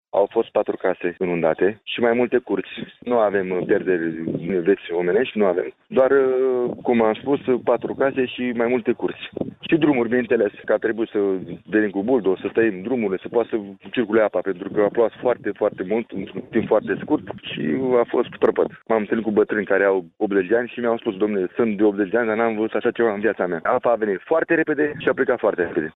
Primarul comunei gălăţene Piscu, Vlad Ştefan, a declarat pentru postul nostru de radio că au fost inundate 4 case şi mai multe curţi, însă nu sunt persoane izolate.